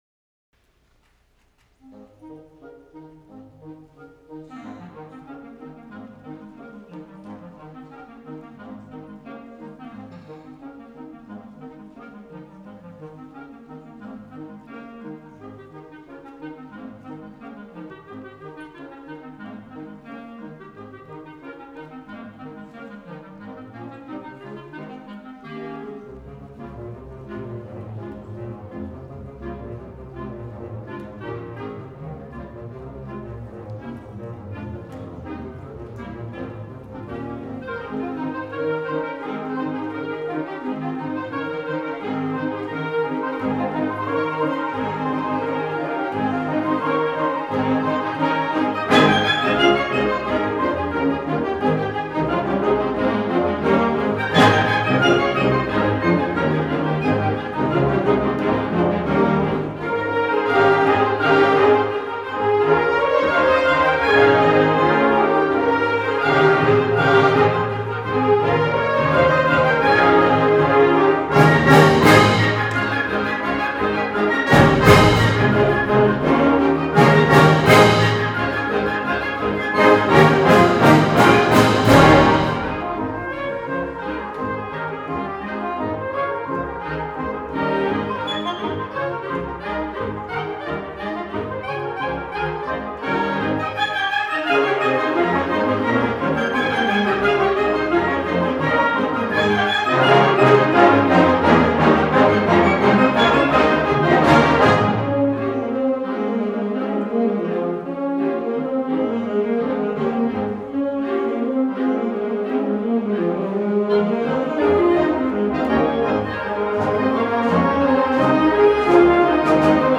The mission of the Southeastern Michigan Wind Ensemble is to provide a performance outlet for music educators and other accomplished musicians, to educate and share music with the local community, and to promote wind and percussion literature as a form of communication and cultural enrichment.